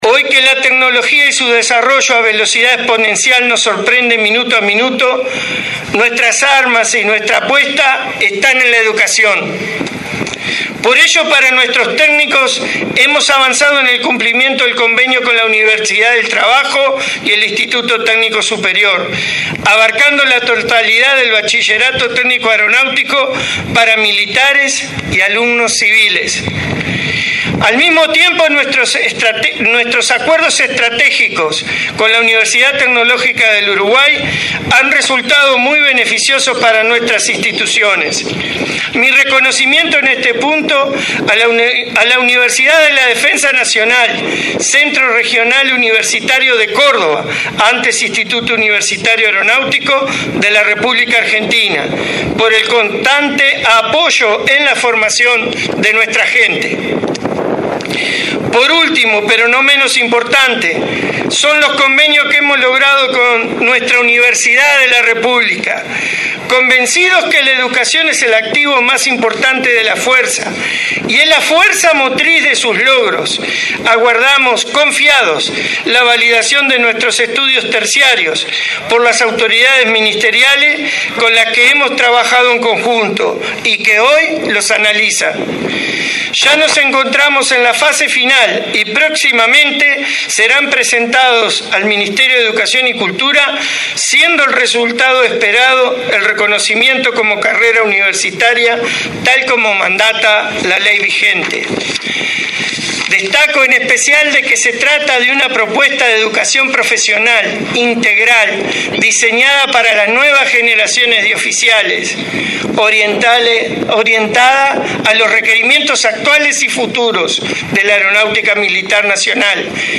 El presidente Tabaré Vázquez estuvo presente en el acto del 105.° aniversario de la Aviación Militar y Día de la Fuerza Aérea Uruguaya, este lunes 19. El comandante en jefe, Alberto Zanelli, destacó los convenios con la Universidad de la República para reconocer como carrera universitaria a los conocimientos brindados, entre otros temas.